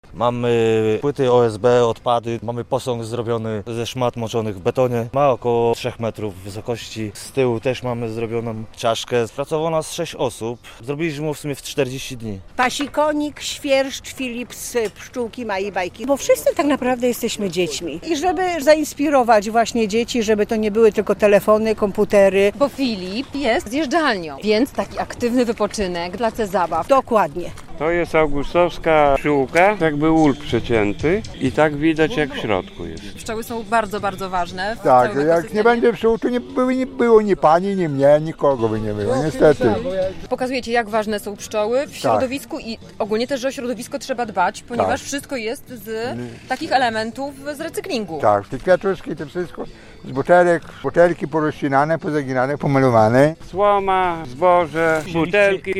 Laureaci Mistrzostw opowiadają o swoich pływadłach